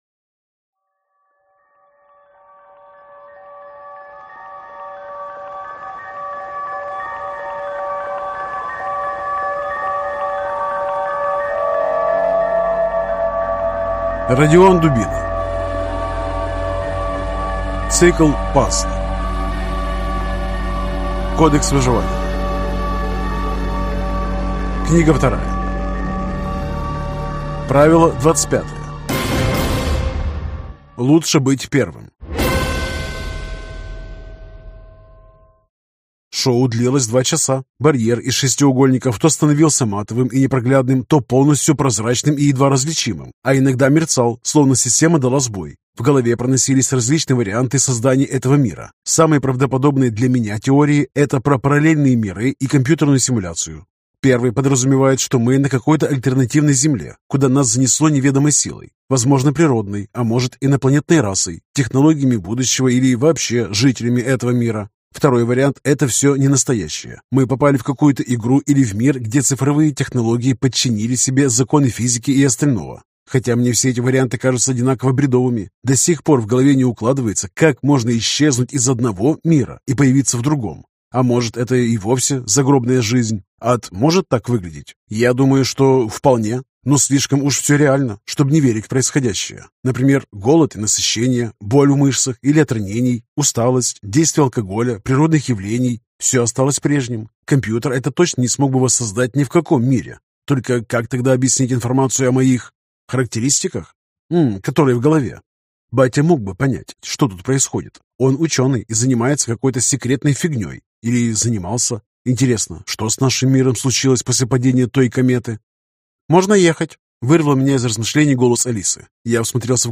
Аудиокнига Кодекс выживания (часть 2) | Библиотека аудиокниг